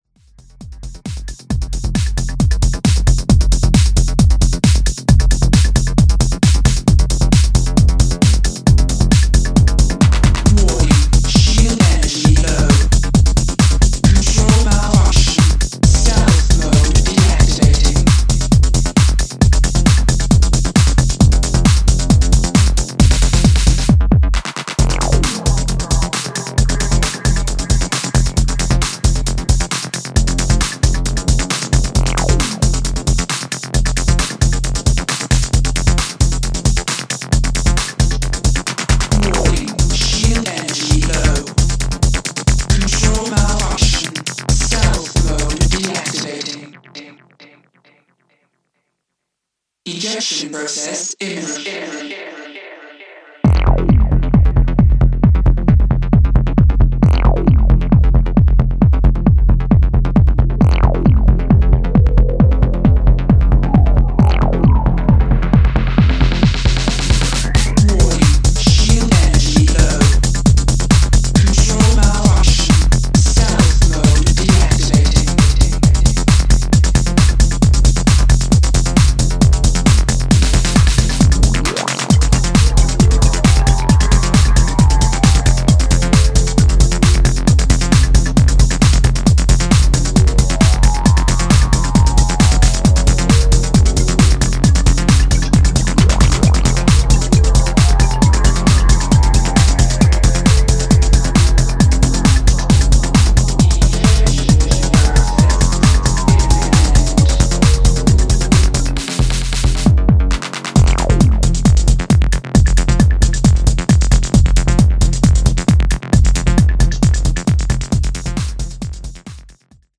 Pure, hypnotic and sharp acid techno
Acid , Electro , Techno